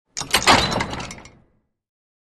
Глухой звук закрытой двери в крепости